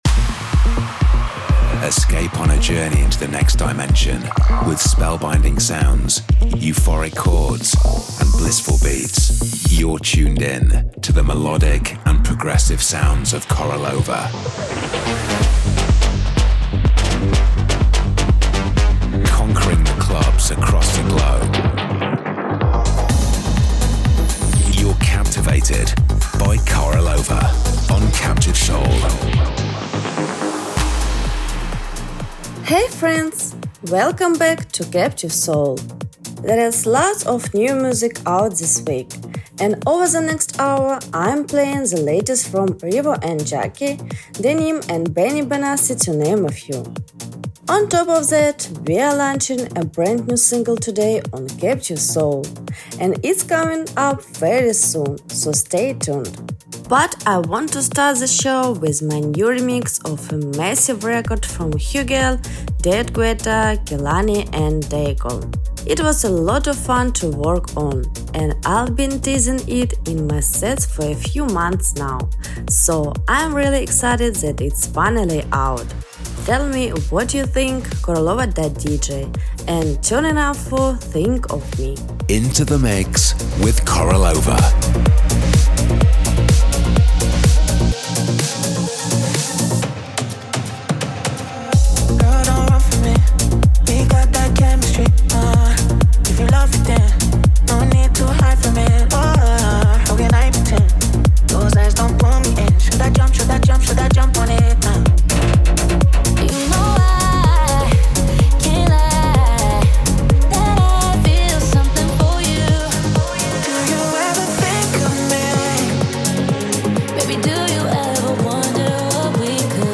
The bi-weekly radio show